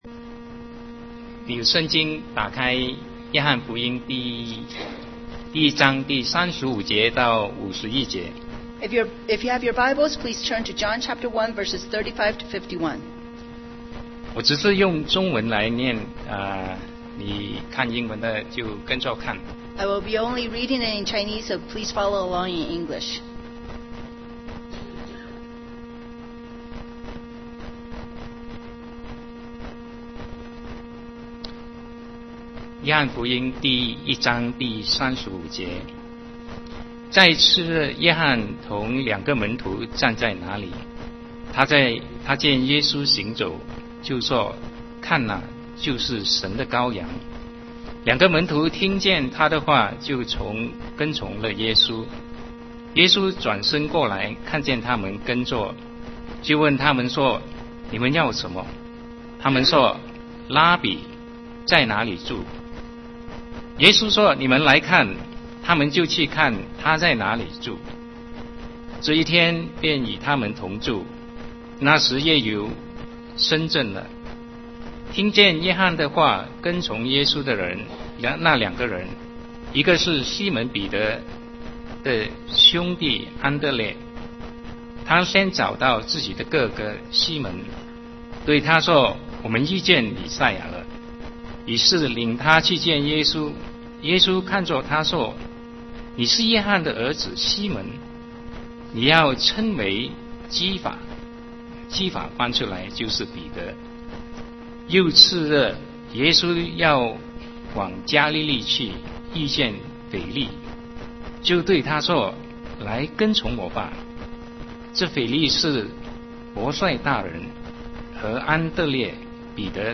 Sermon 2011-03-27 The Testimony of the Disciples